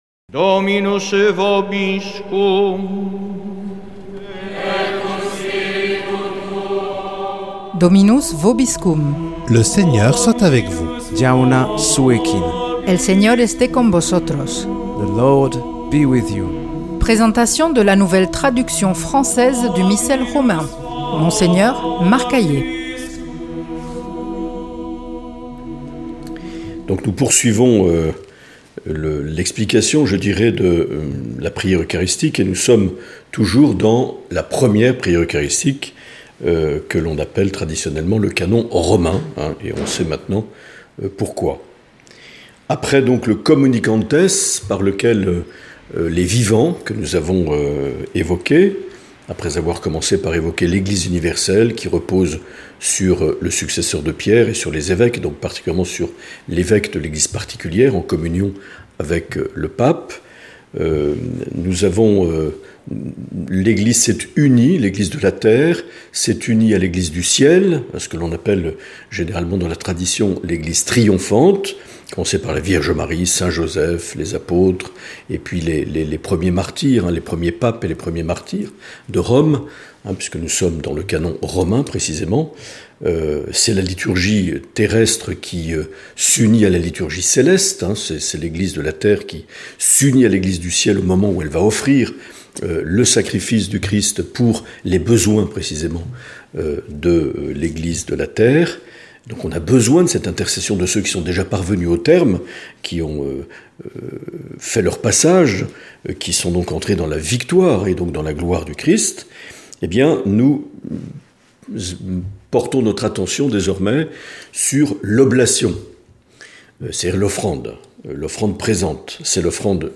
Présentation de la nouvelle traduction française du Missel Romain par Mgr Marc Aillet